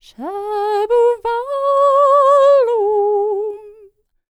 K CELTIC 31.wav